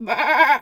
pgs/Assets/Audio/Animal_Impersonations/sheep_2_baa_08.wav at master
sheep_2_baa_08.wav